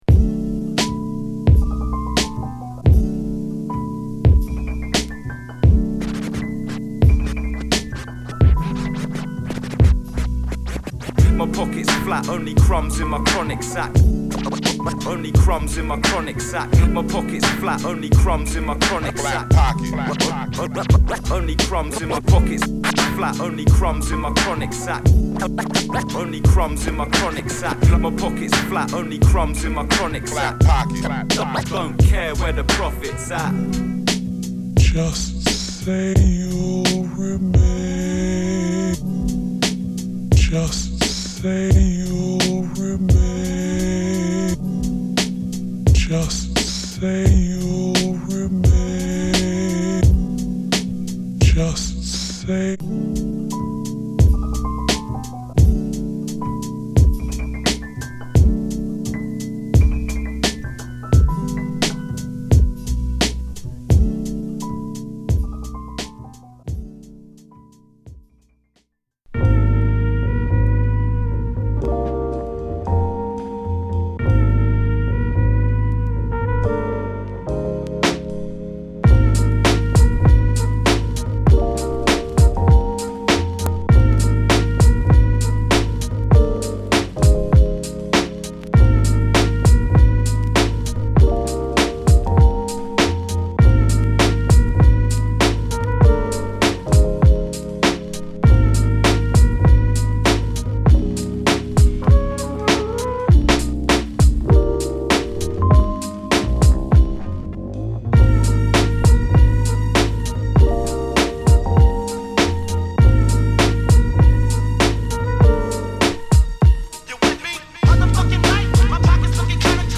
クールトーンなジャジーインストを満載！